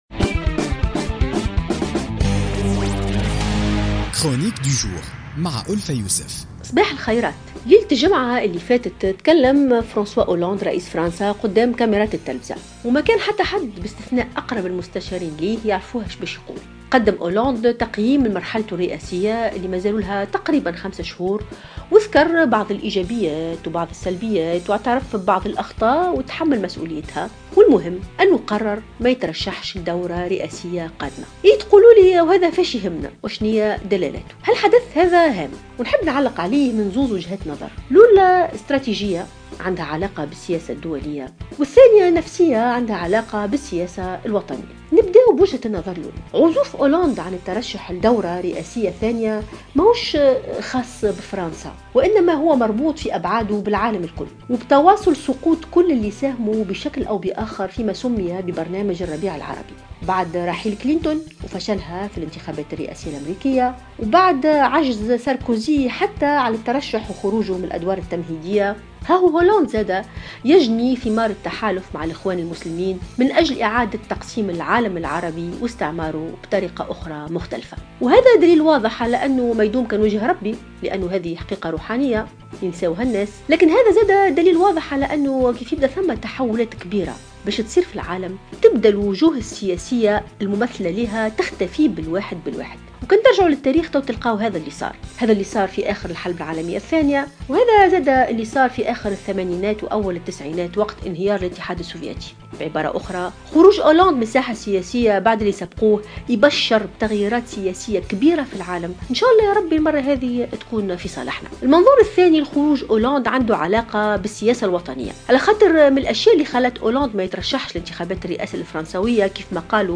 تطرقت الباحثة ألفة يوسف في افتتاحية اليوم الاثنين 5 ديسمبر 2016 إلى قرار الرئيس الفرنسي فرانسوا هولند عدم الترشح للانتخابات الرئاسية المقبلة.